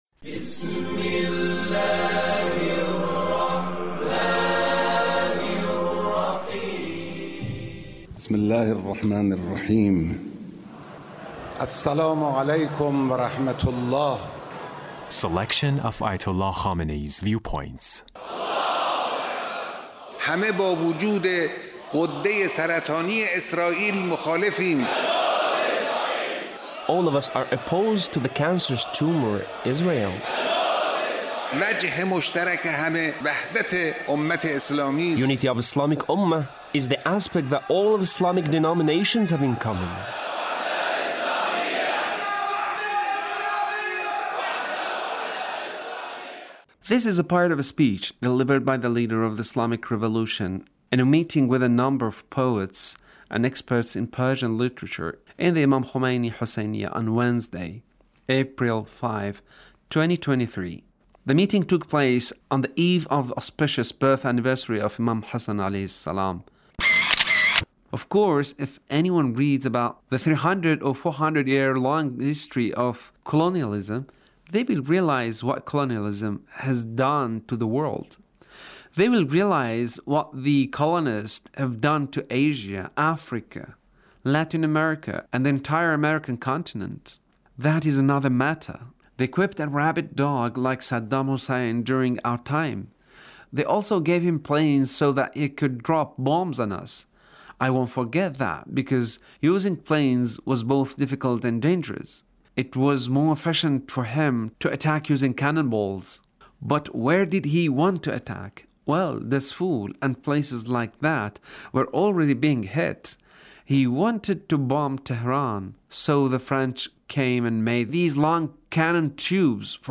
Leader's Speech on Poet's Gathering